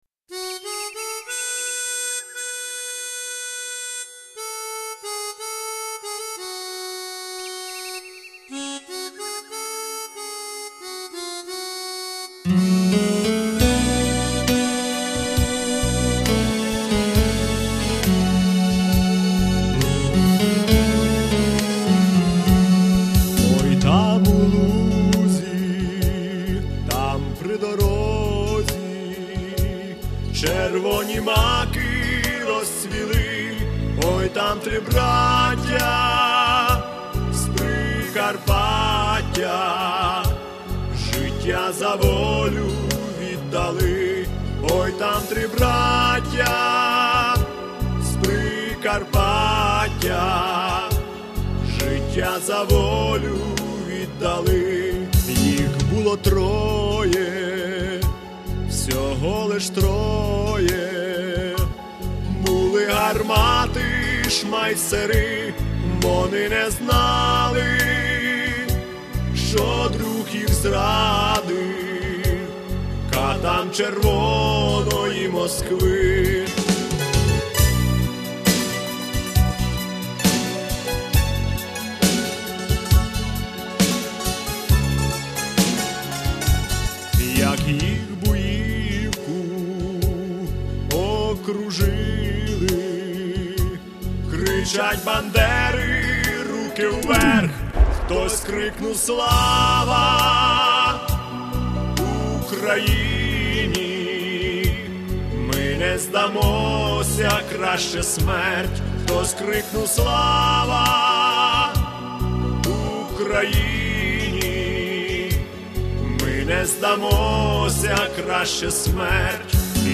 Плюсовий запис
Мелодійно, як в казці... Дякую.